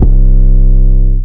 808 - HORROR MOVIE.wav